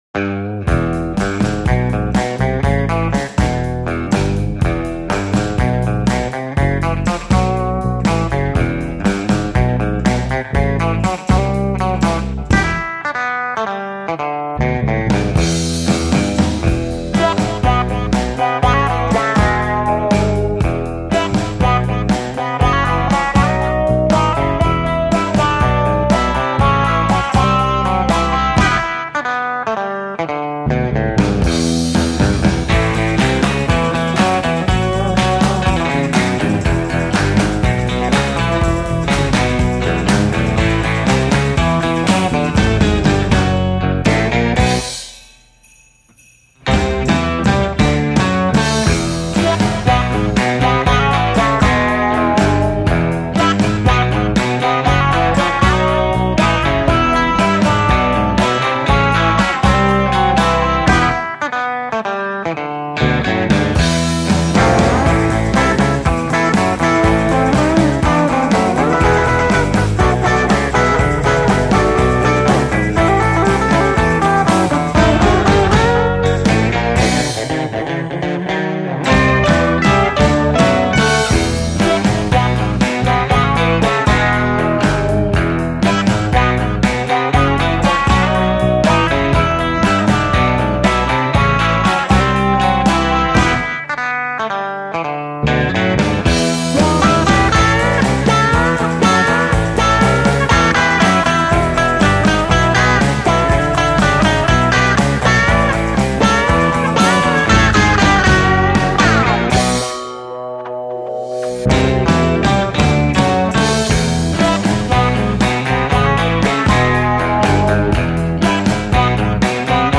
instrumental trilogy